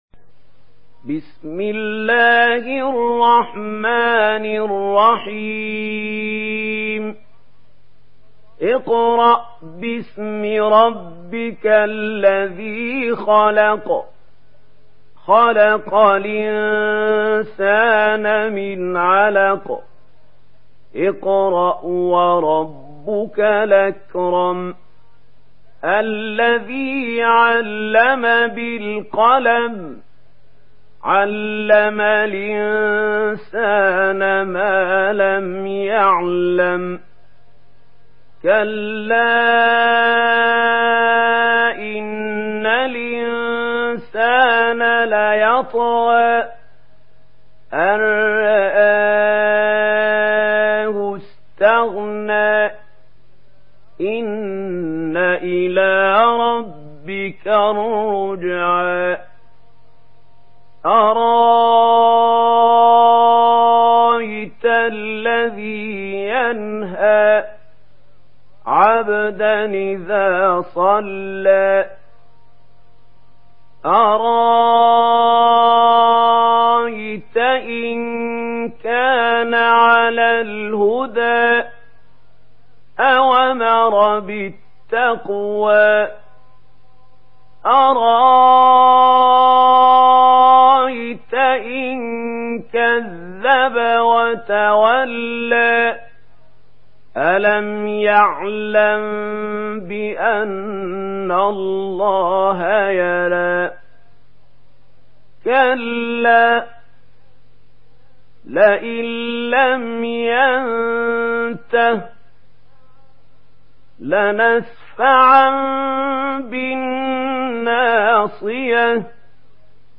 تحميل سورة العلق بصوت محمود خليل الحصري
مرتل ورش عن نافع